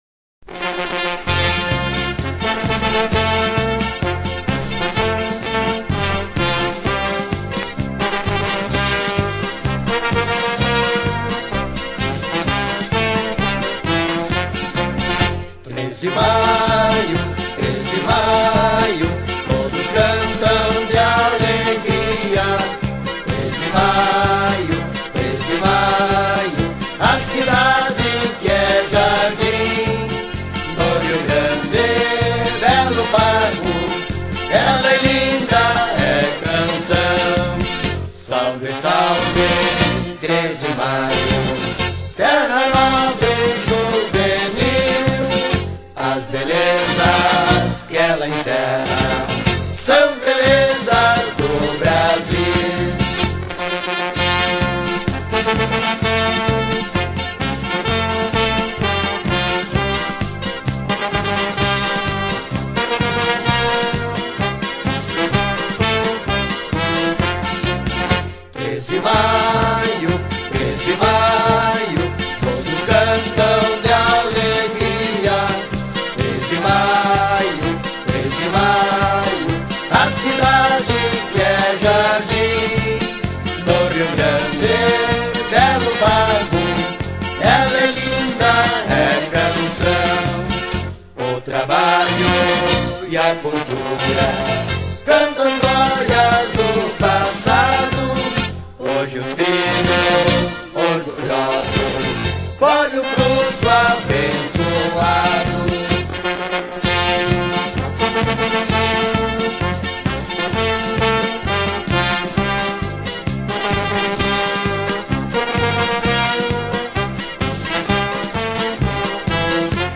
Hino